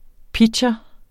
pitcher substantiv, fælleskøn Bøjning -en, -e, -ne Udtale [ ˈpidɕʌ ] Oprindelse fra engelsk pitcher 'kaster' Betydninger 1.